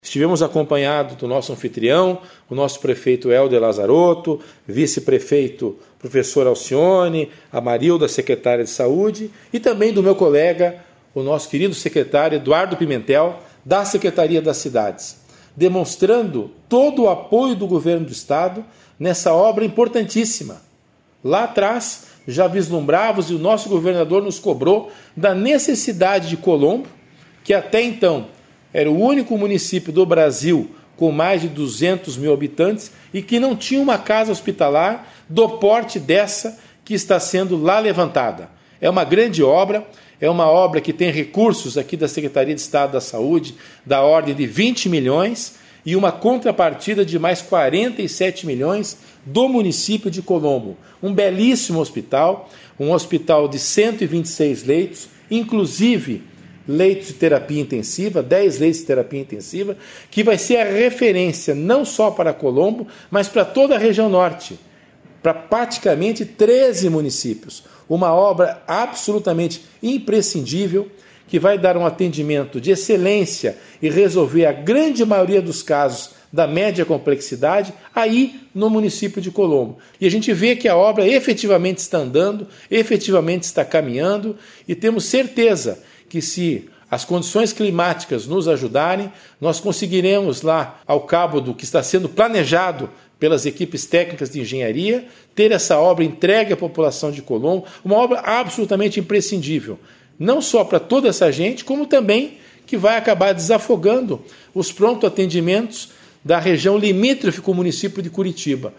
Sonora do secretário da Saúde, César Neves, sobre a construção do Hospital Geral de Colombo